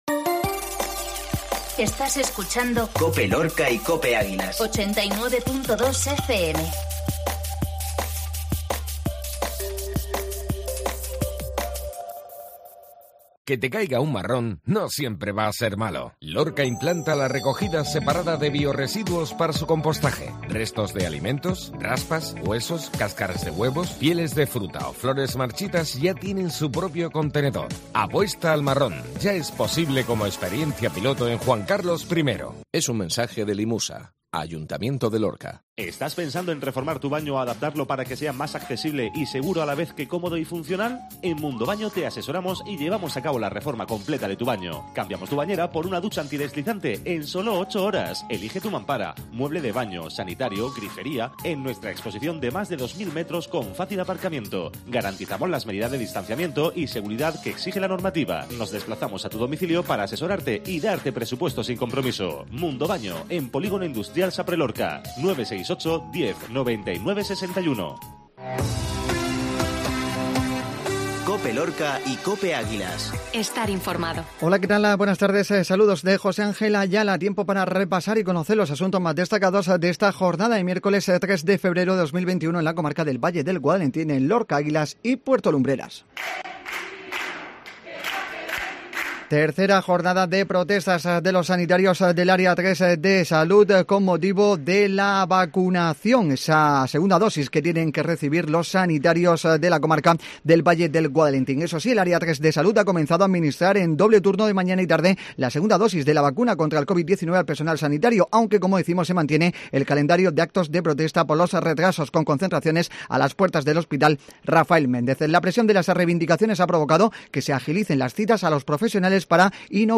INFORMATIVO MEDIODÍA COPE LORCA